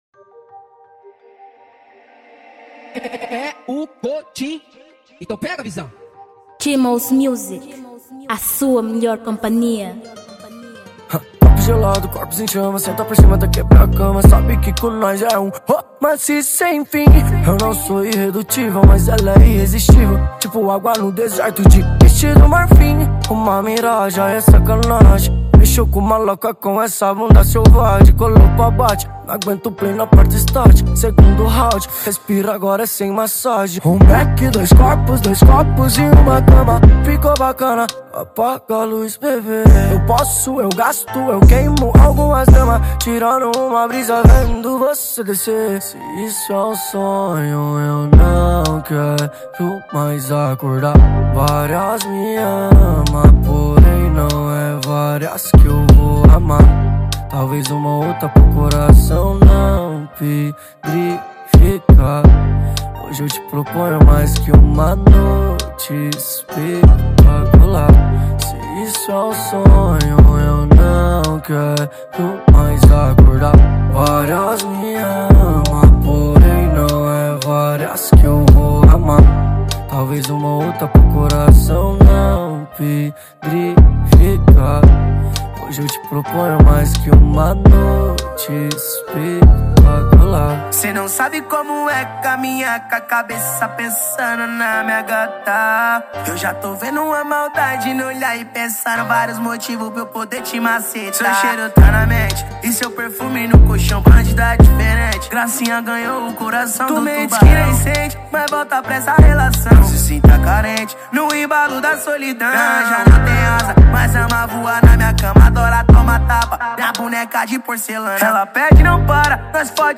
2023 Gênero: Funk Tamanho